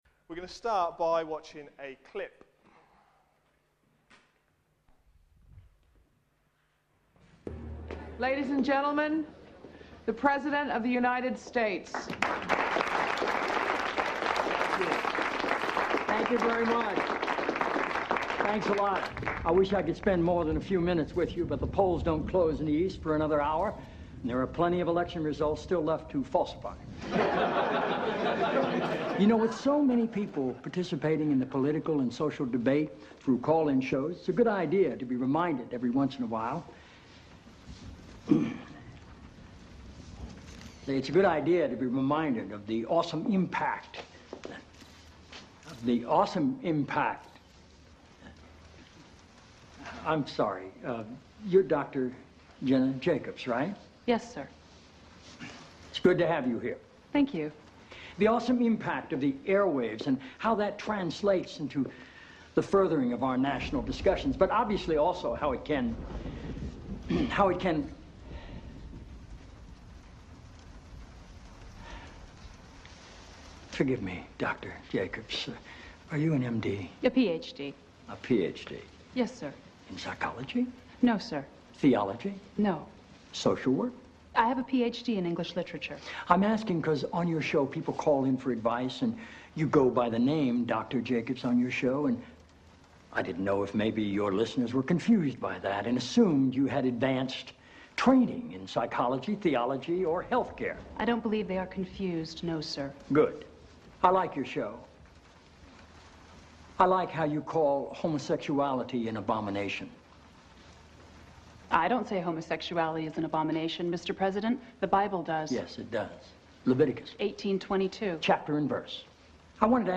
A sermon preached on 26th September, 2010, as part of our God's Big Picture series.